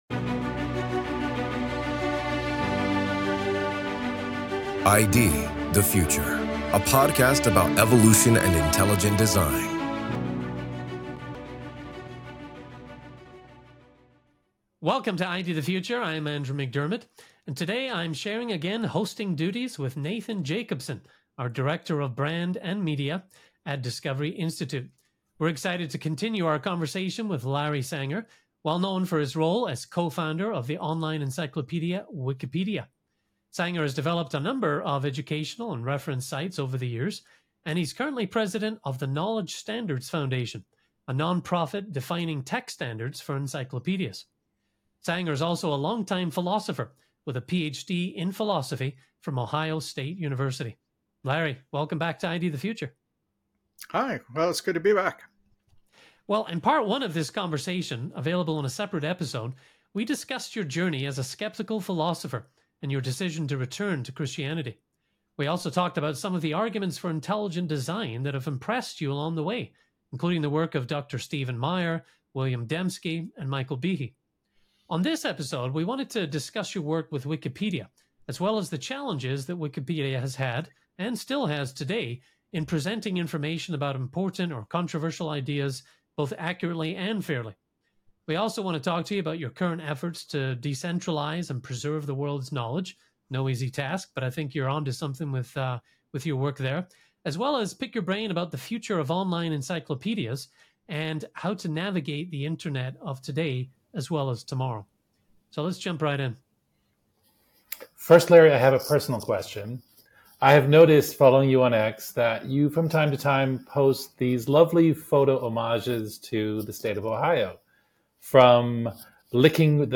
This is Part 2 of a two-part conversation.